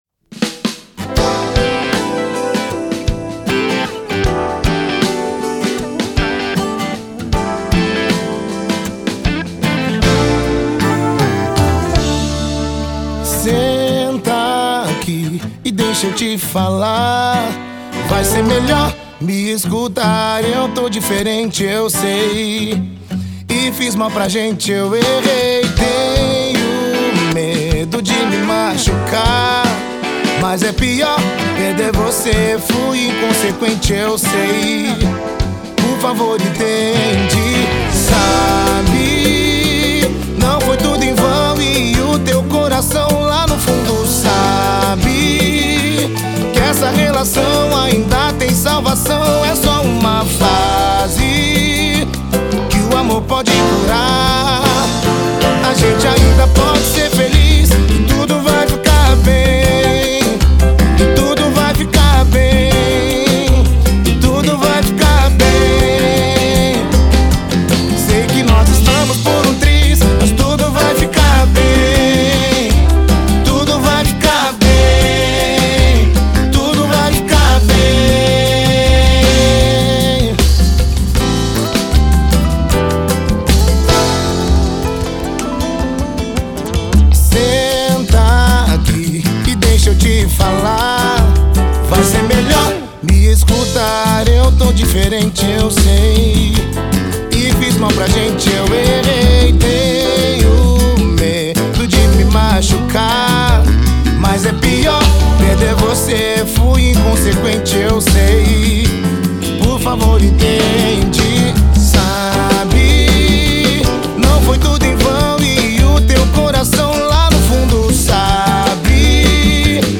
EstiloPagode